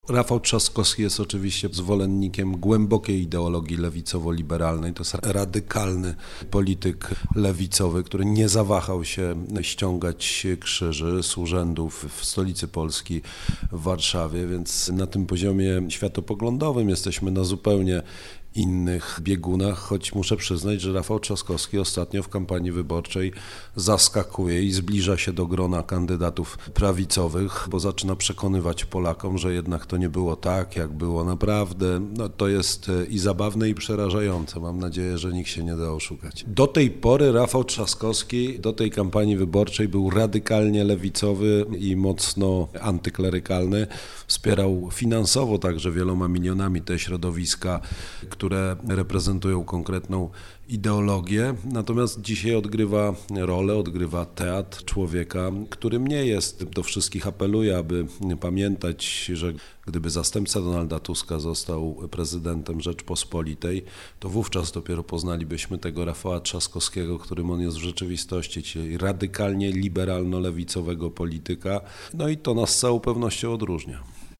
Karol Nawrocki – kandydat na urząd prezydenta popierany przez PiS był gościem Radia Rodzina. Rozmawialiśmy o patriotyzmie, kierunku polskiej edukacji, redukcji liczby godzin religii, światopoglądzie.